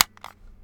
Added switch sound.